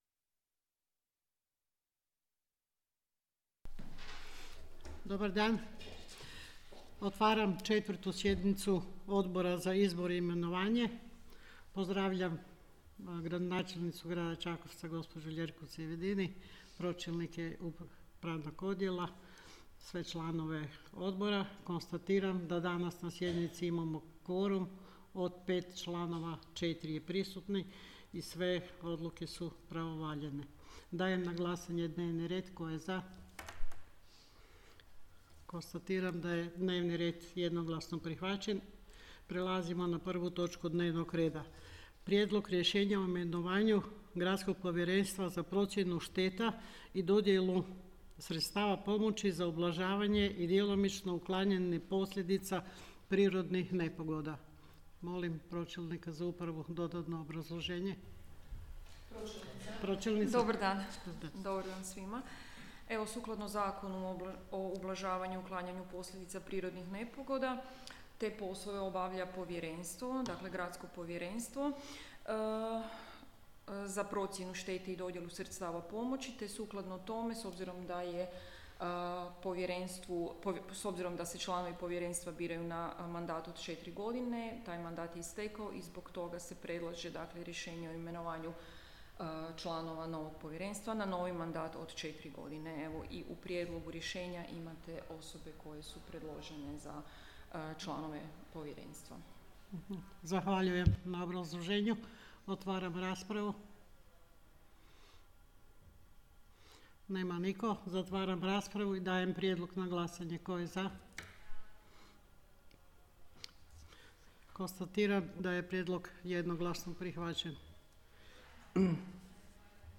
4. sjednica Odbora za izbor i imenovanje
Obavještavam Vas da će se 4. sjednica Odbora za izbor i imenovanje Gradskog vijeća Grada Čakovca održati 23. veljače 2026. godine (ponedjeljak) u 11:30 sati u prostorijama Uprave Grada Čakovca, Kralja Tomislava 15, Čakovec.